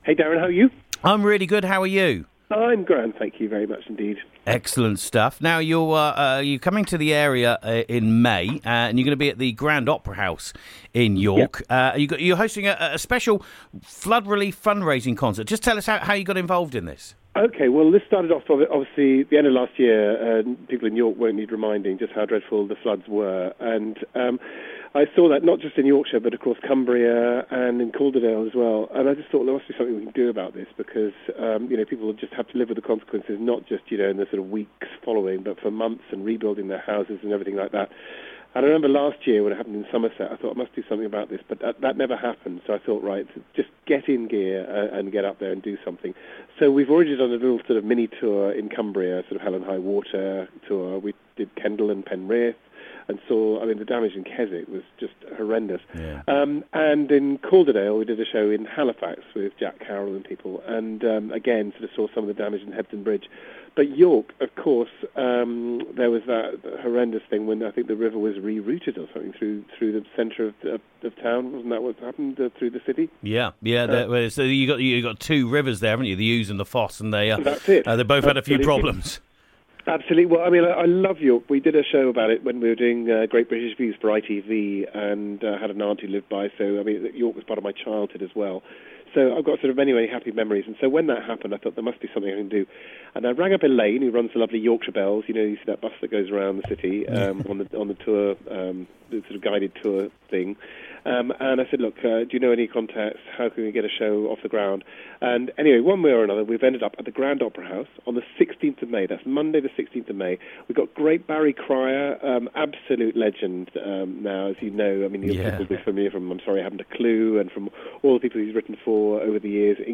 Legendary impressionist and satarist Rory Bremner